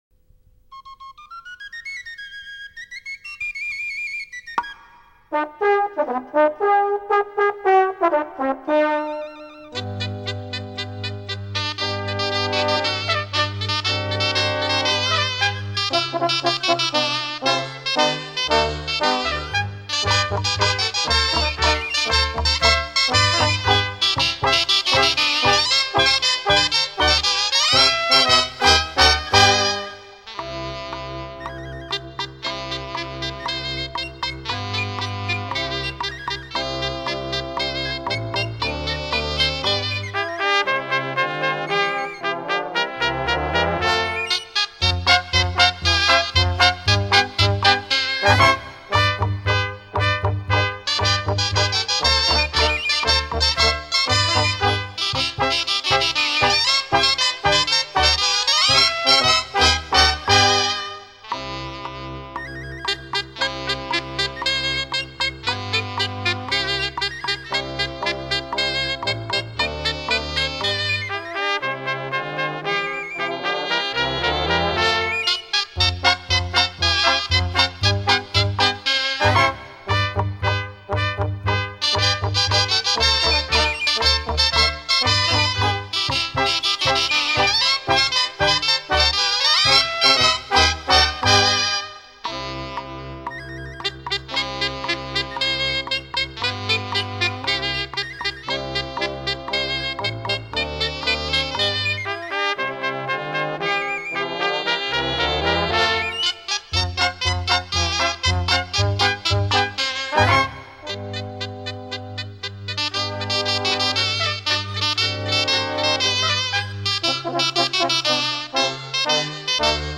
Cançó instrumental:
18-Els-mitjons-instr.mp3